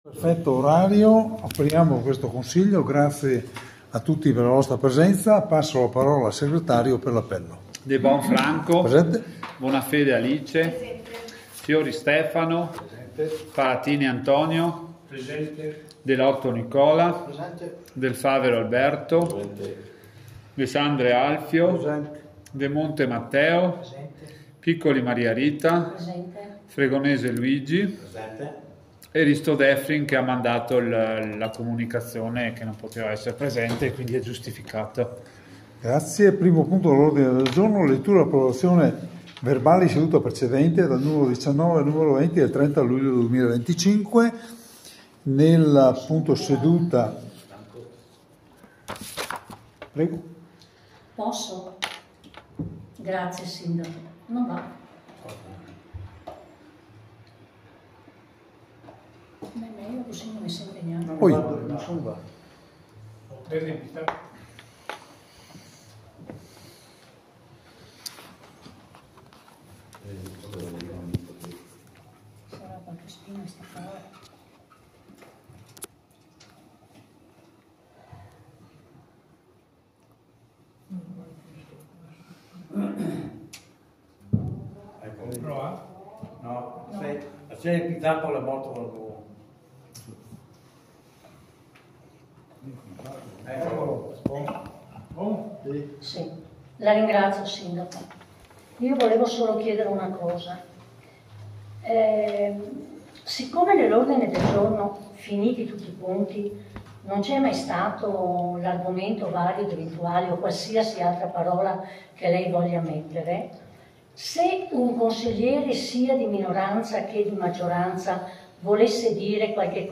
Registrazione audio Consiglio Comunale